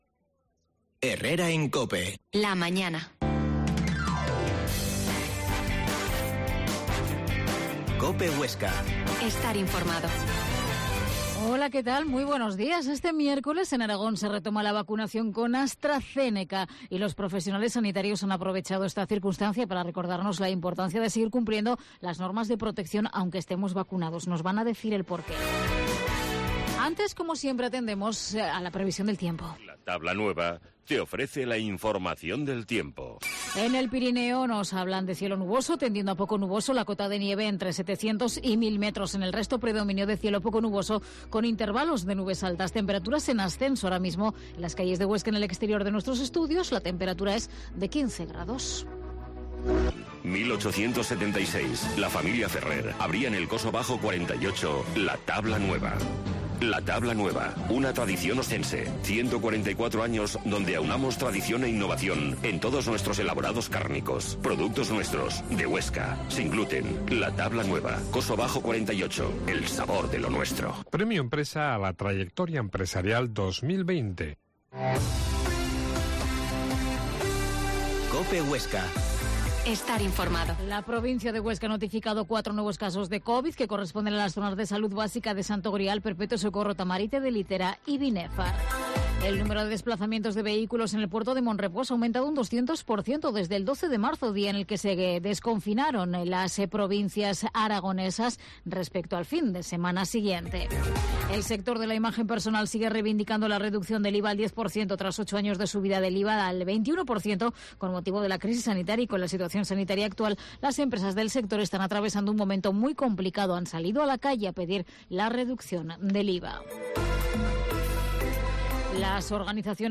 La Mañana en COPE Huesca - Magazine